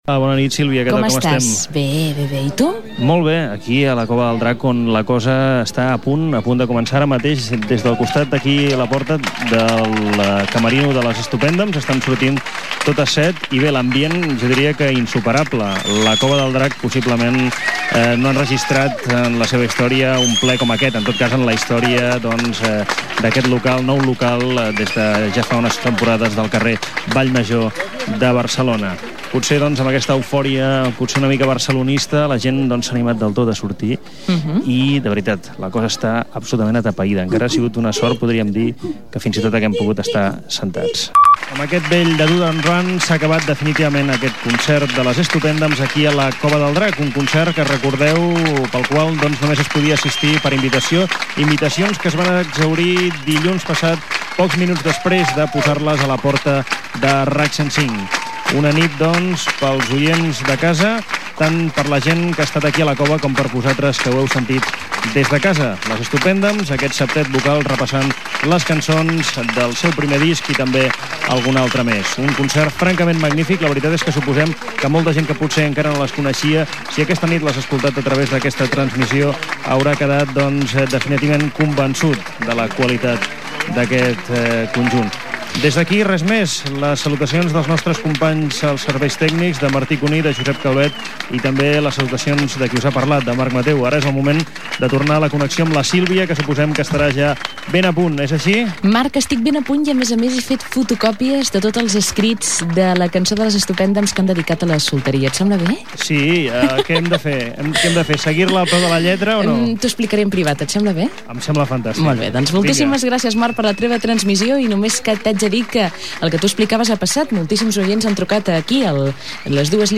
Connexió en directe al concert que Stupendams oferia a La Cova del Drac de Barcelona, introducció, final de connexió, i presentació de tema musical.
Musical